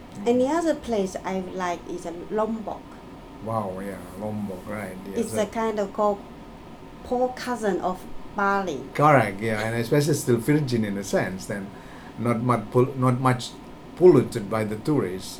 S1 = Taiwanese female S2 = Indonesian male Context: They are talking about interesting places to visit in Indonesia.
POLluted by the tourists Intended Words: still virgin Heard as: in the region Discussion: S2 pronounces virgin with [ɪr] rather than [ɜ:] in the first syllable (in what might be described as a spelling pronunciation).